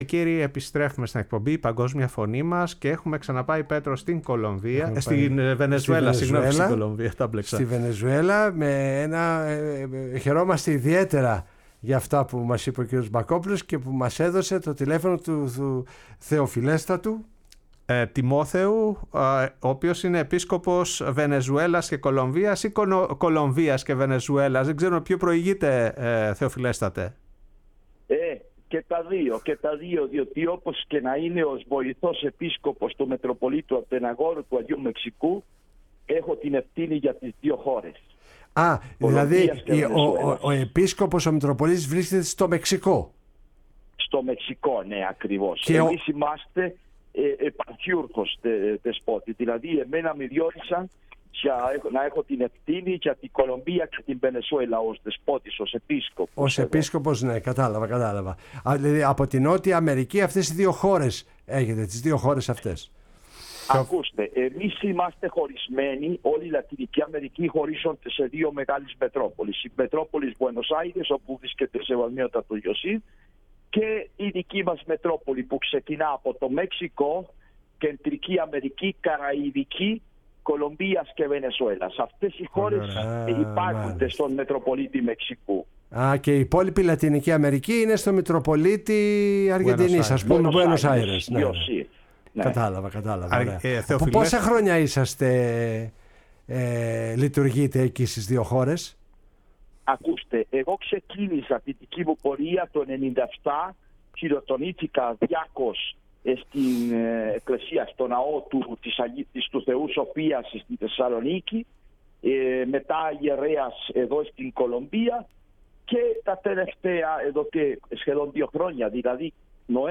Για την Ορθοδοξία, την Ελλάδα και το έργο που επιτελεί στην Βενεζουέλα και στην Κολομβία μίλησε ο Θεοφιλέστατος Επίσκοπος ‘Ασσου κύριος Τιμόθεος